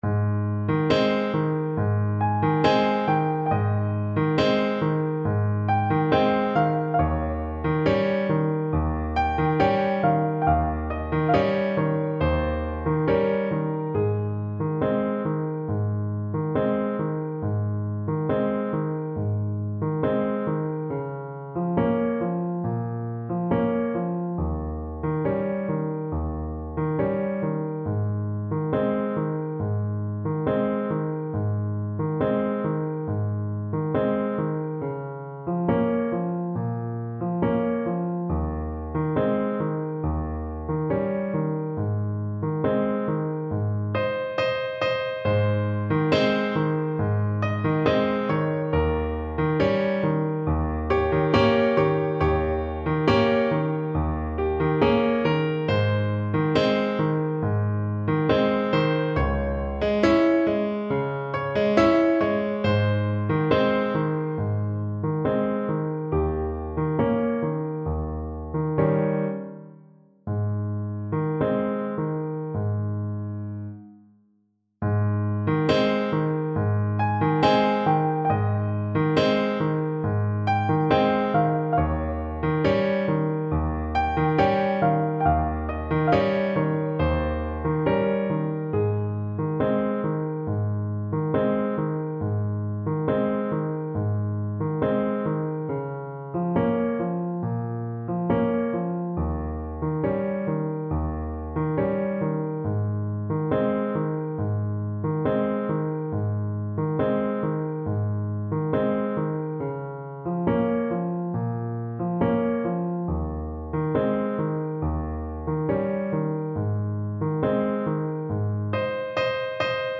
2/4 (View more 2/4 Music)
Andantino = c. 69 (View more music marked Andantino)
Classical (View more Classical Tenor Saxophone Music)
Neapolitan Songs for Tenor Sax